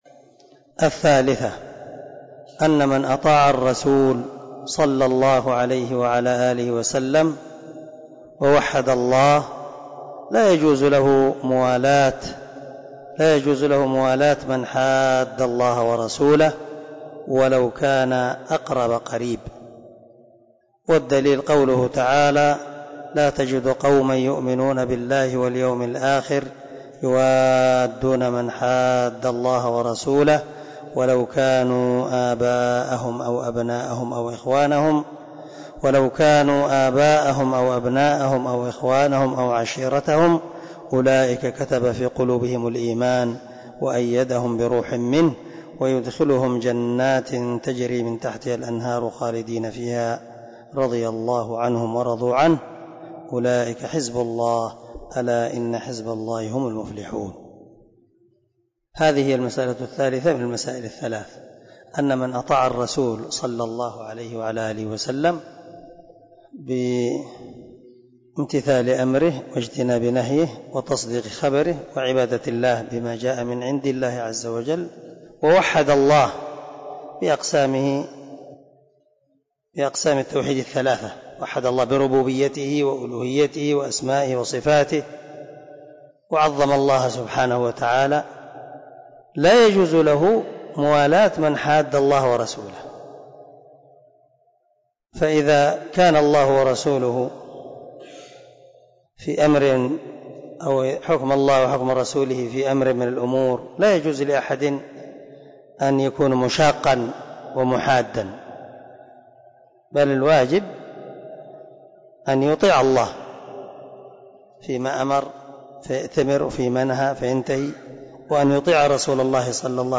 🔊 الدرس 6 من شرح الأصول الثلاثة